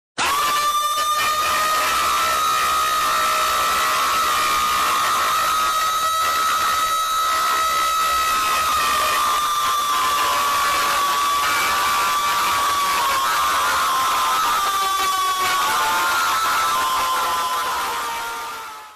Sound Effects
Bird Screams For Life